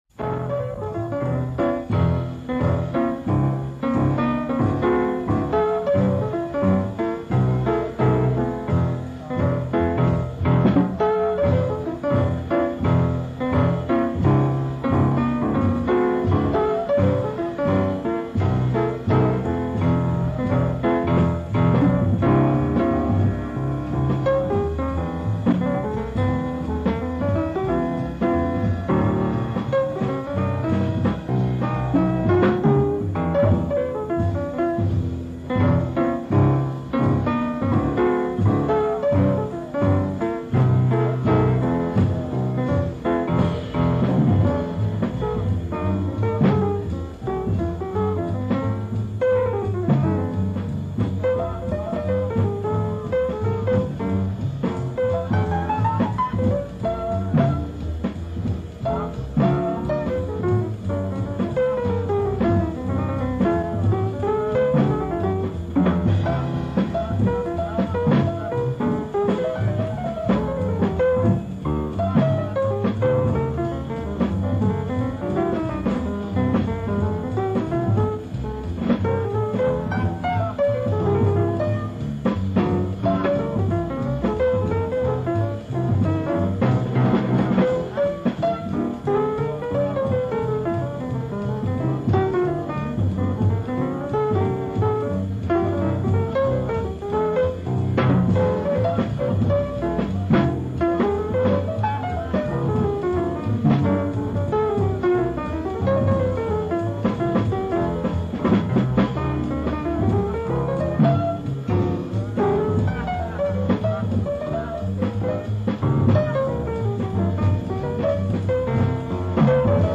Birdland Club, New York City.
bass
Drums